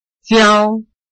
臺灣客語拼音學習網-客語聽讀拼-饒平腔-開尾韻
拼音查詢：【饒平腔】siau ~請點選不同聲調拼音聽聽看!(例字漢字部分屬參考性質)